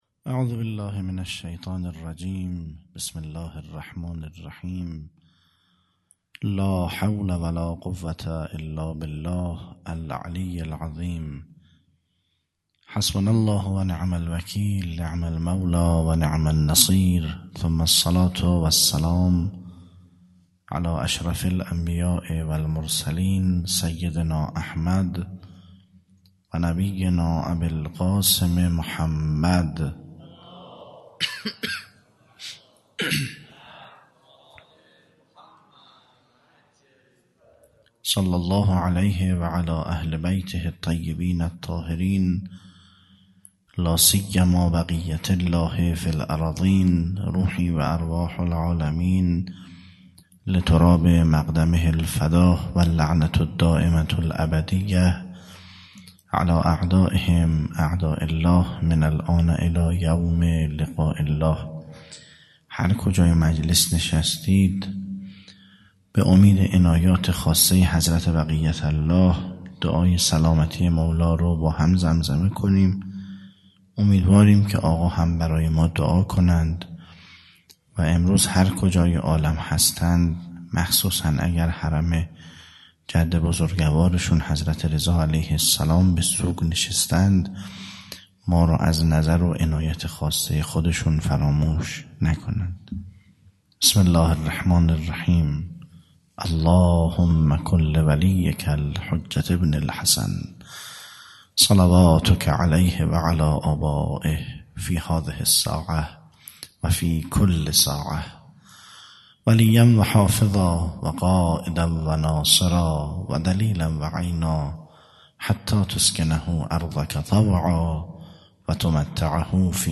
خیمه گاه - هیئت بچه های فاطمه (س) - سخنرانی